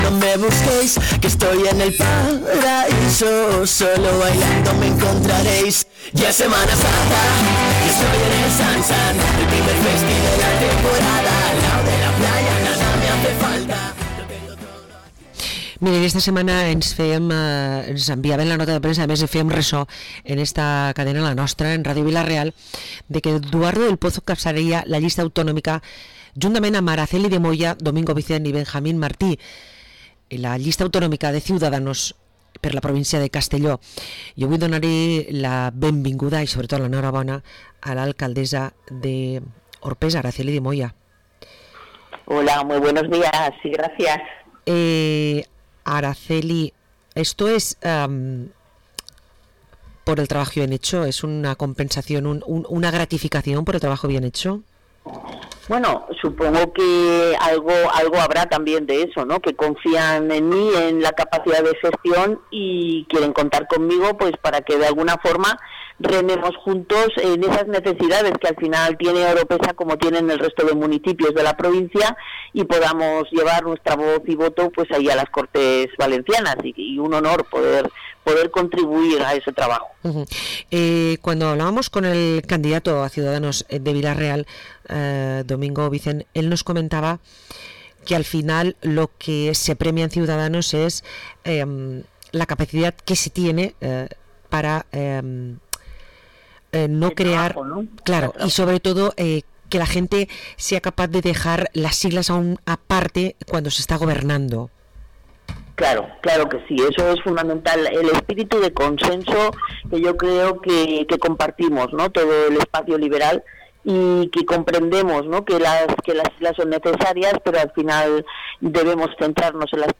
Entrevista a l’alcaldessa d’Oropesa, Araceli de Moya